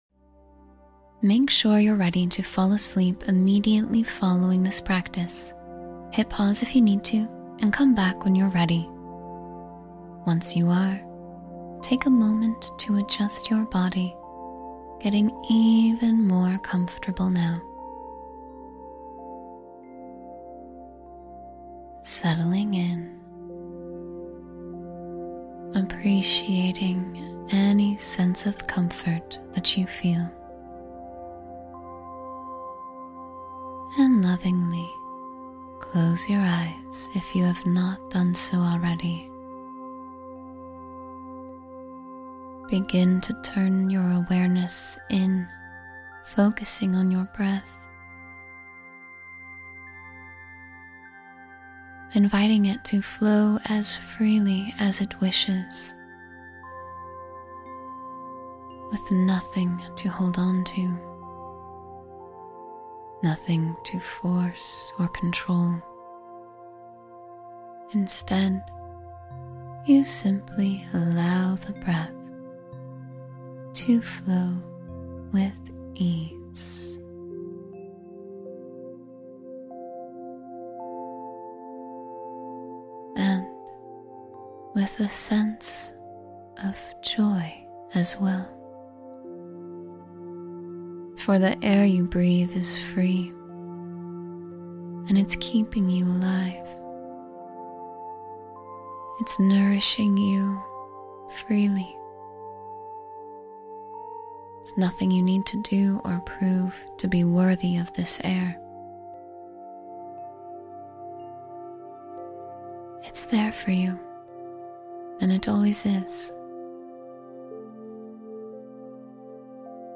Drift to Sleep with Uplifting Thoughts — Guided Meditation for Restful Sleep